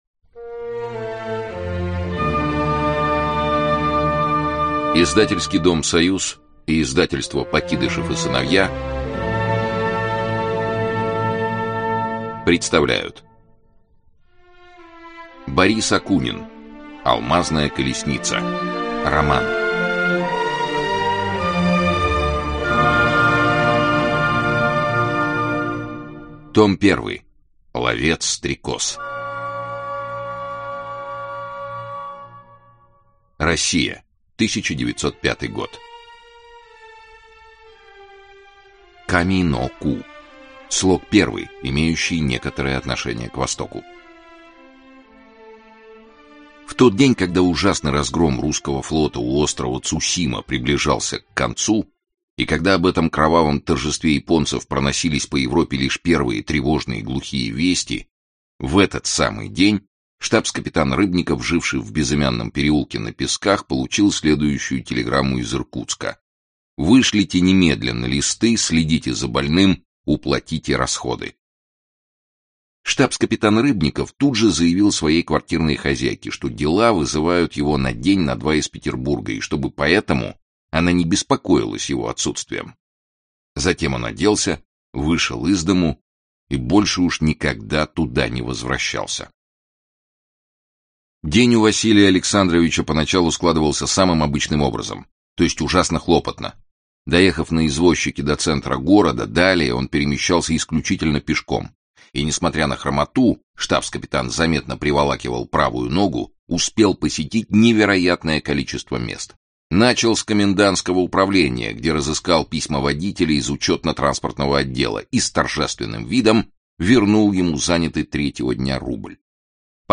Аудиокнига Алмазная колесница - купить, скачать и слушать онлайн | КнигоПоиск